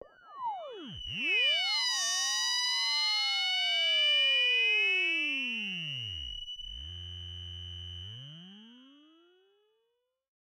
标签： MIDI-速度-64 F6 MIDI音符-90 赤-AX80 合成器 单票据 多重采样
声道立体声